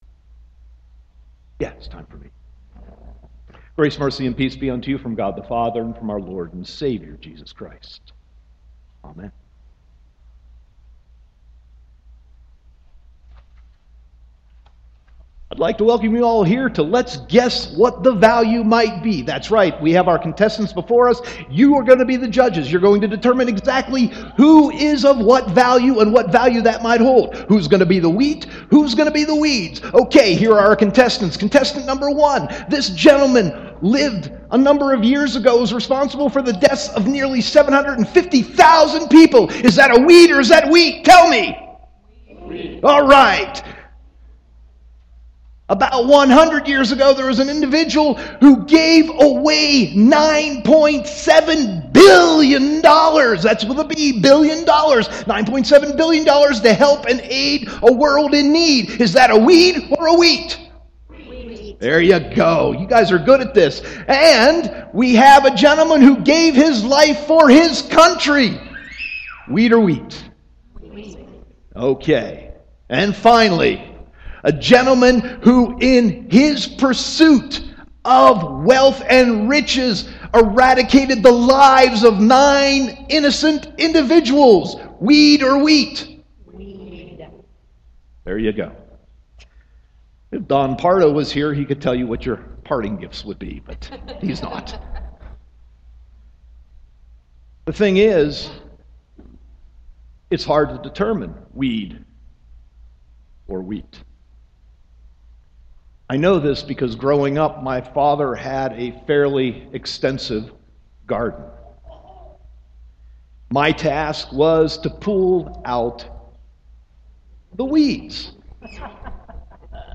Sermon 7.20.2014